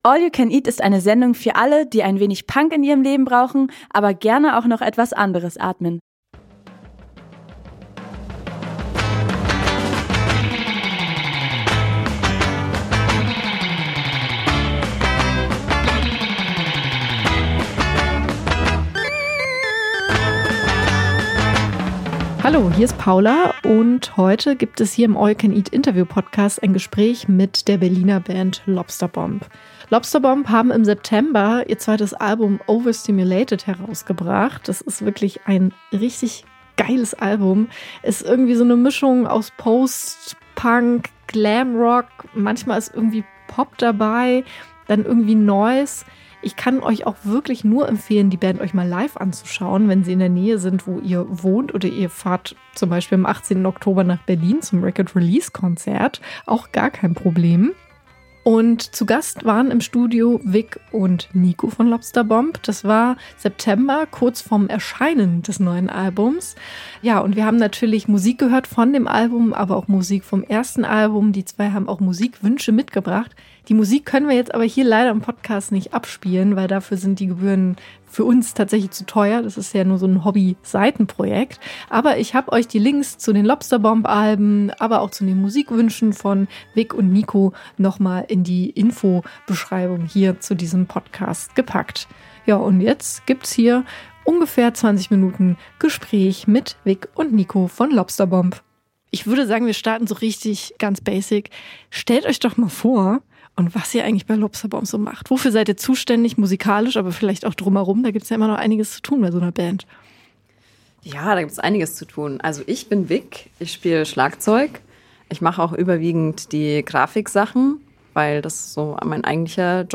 Im Interview sind sie dafür entspannt und plaudern mit uns über ihr neues Album Overstimulated, wer bei ihnen niemals den Transporter fährt und Musik als Ventil eigener Gefühle.